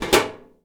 metal_tin_impacts_movement_rattle_03.wav